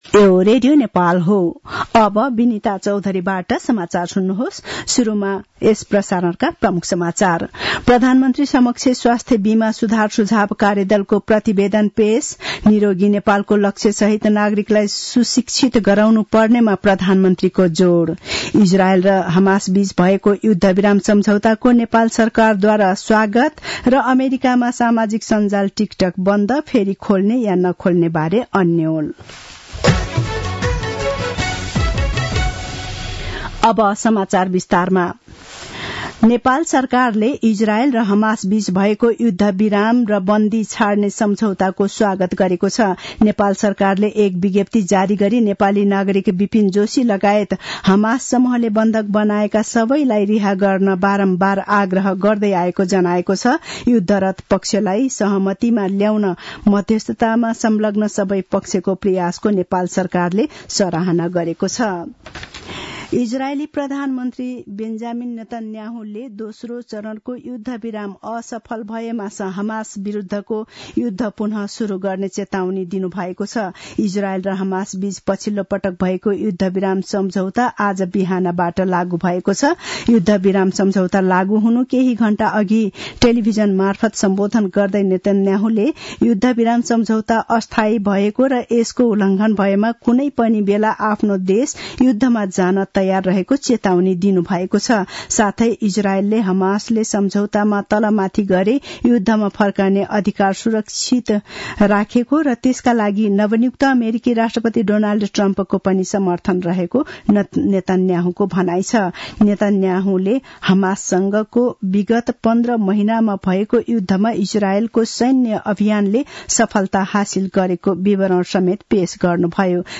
दिउँसो ३ बजेको नेपाली समाचार : ७ माघ , २०८१